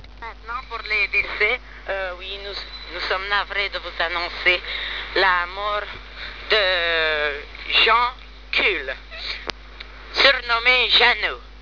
Nos blagues :